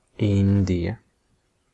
Ääntäminen
Ääntäminen France (Paris): IPA: [ɛ̃nd] Tuntematon aksentti: IPA: /ɛ̃d/ Haettu sana löytyi näillä lähdekielillä: ranska Käännös Ääninäyte 1.